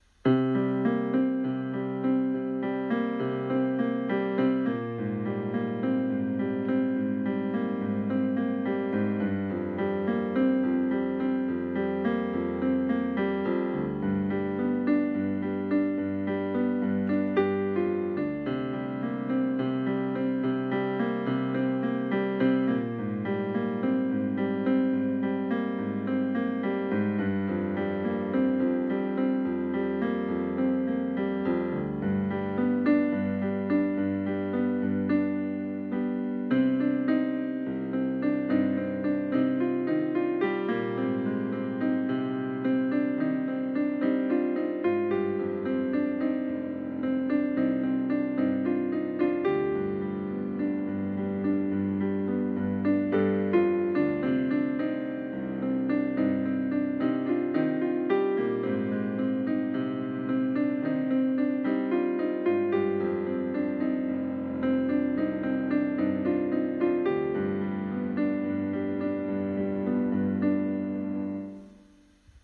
言っておくけどピアノ初心者だからね！
リズム感皆無
ミスタッチも余裕です。
※加えてスマホのボイスメモで録ってるから音質悪いです